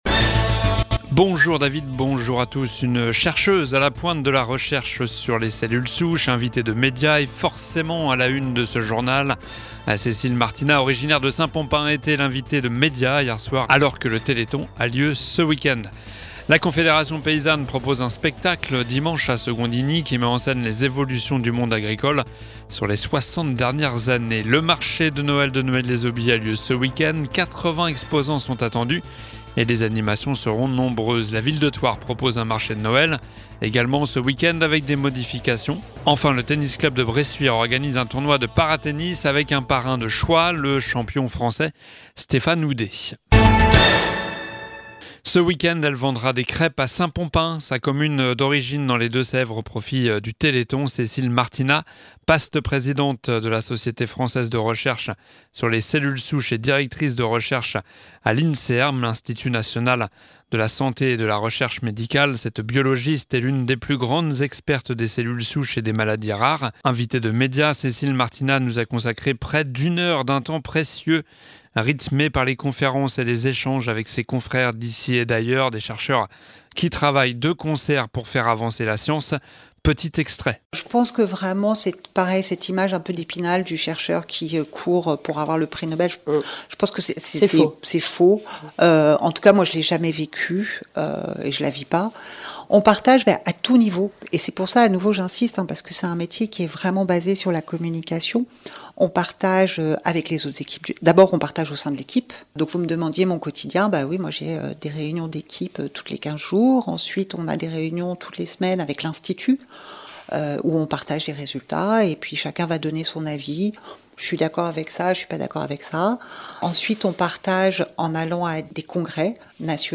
Journal du vendredi 08 décembre (midi)